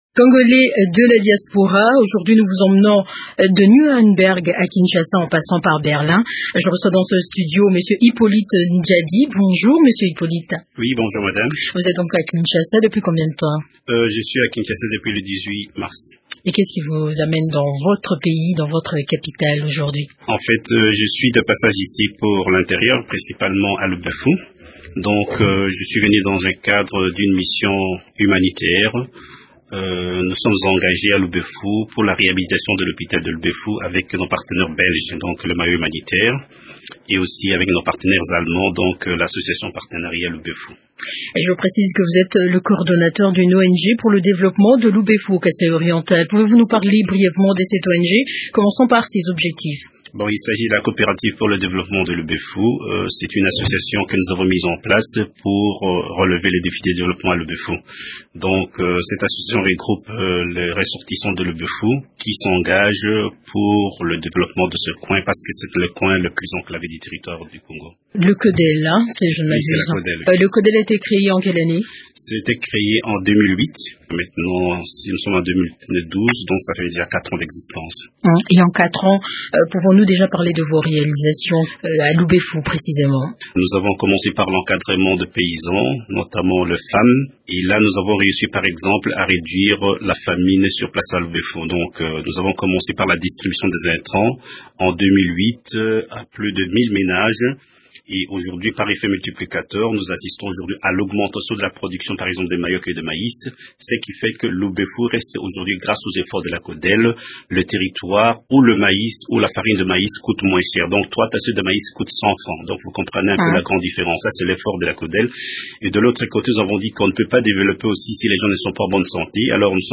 interrogé